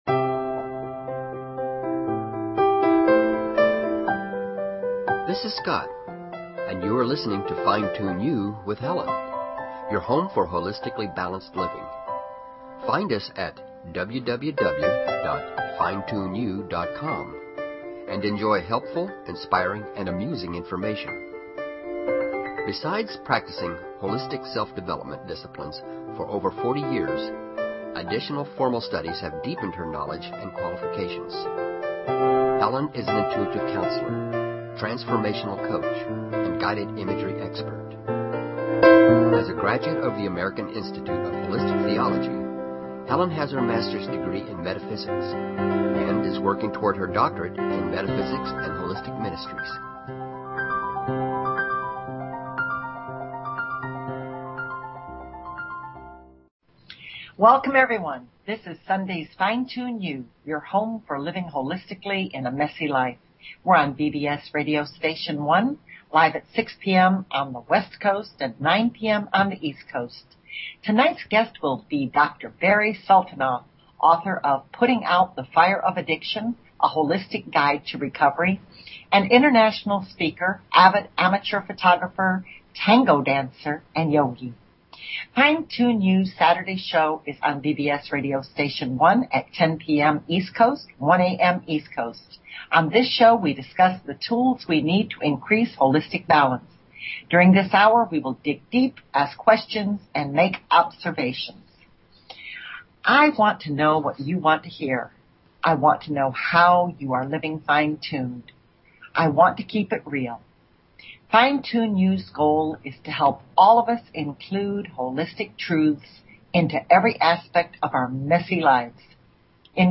Talk Show Episode, Audio Podcast, Fine_Tune_You and Courtesy of BBS Radio on , show guests , about , categorized as